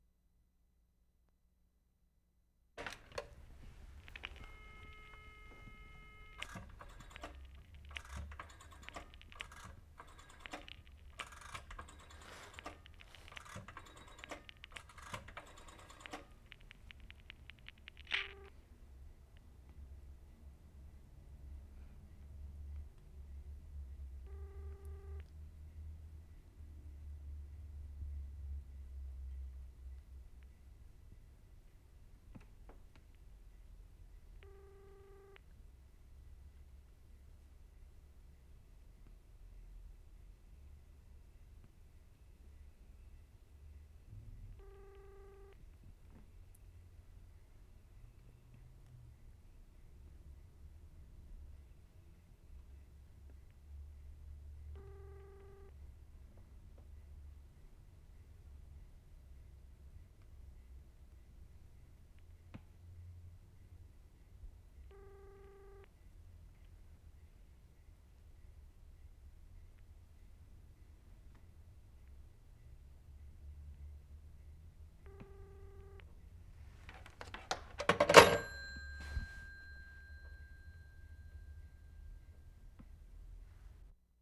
Stockholm, Sweden Feb. 10, 12/75
Dialing telephone and ringback tone.